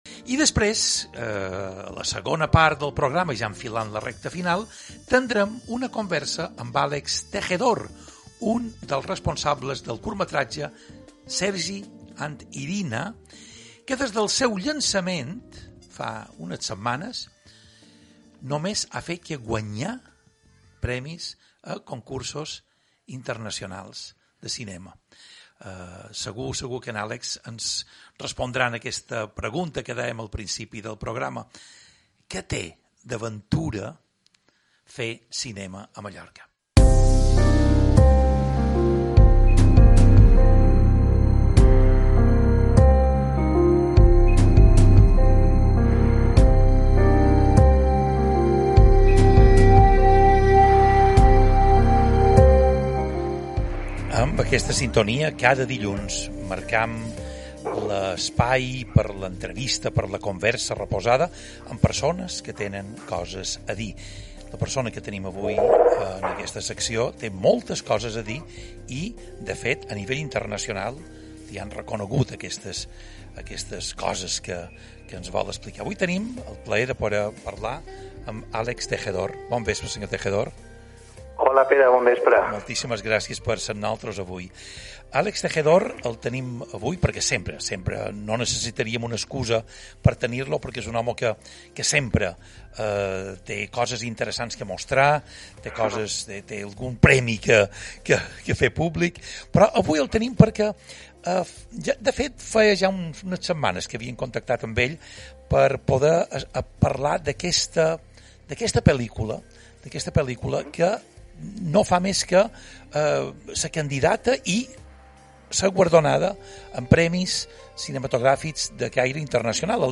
Visita iVoox para escuchar la entrevista: